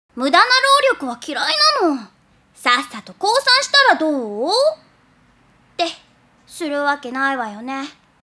サンプルボイスの１つめは戦闘画面、２つめはイベントシーンの音声です。
サンプルボイス1